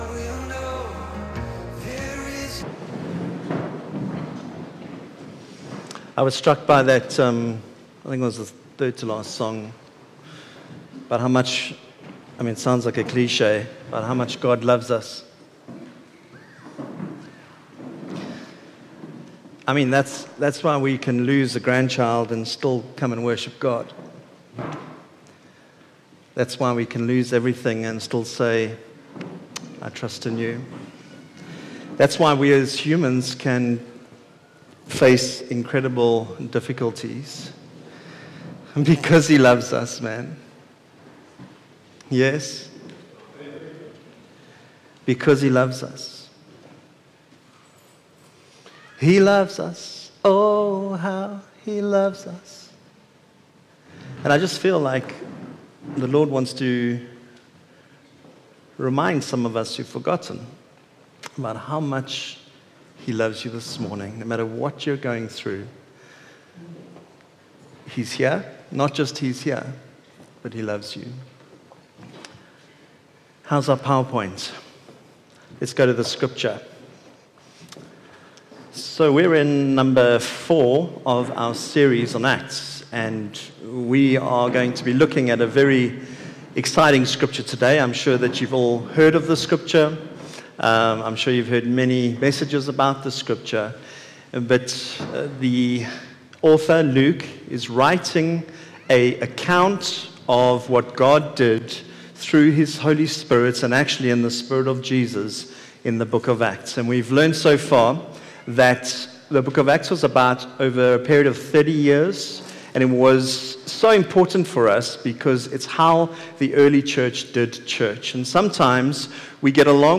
Sunday Service – 29 January
Sermons